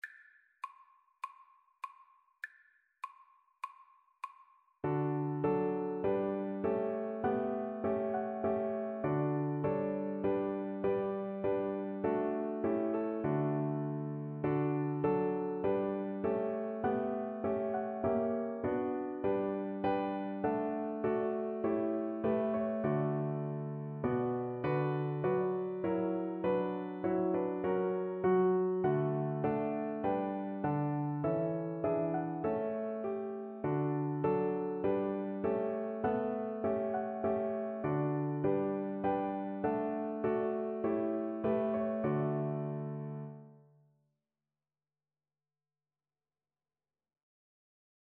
4/4 (View more 4/4 Music)
D major (Sounding Pitch) (View more D major Music for Violin )